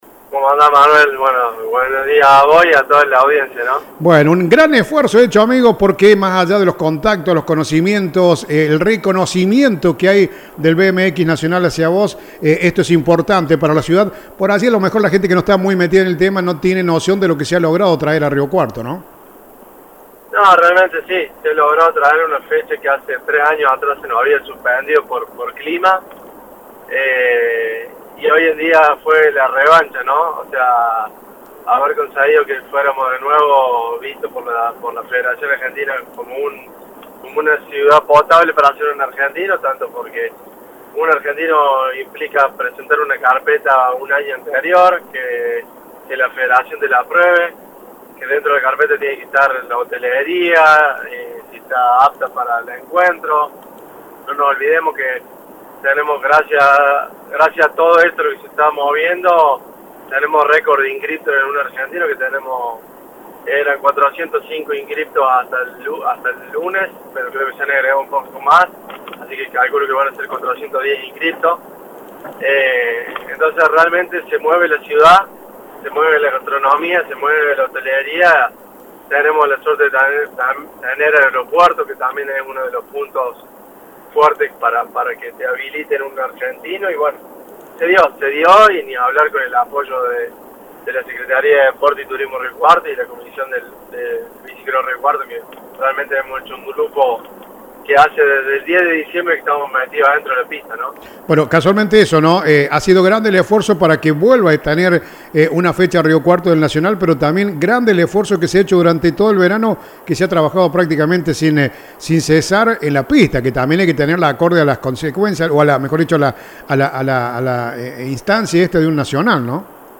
Así dialogaba con nosotros: